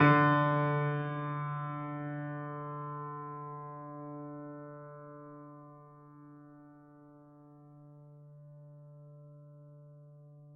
piano-sounds-dev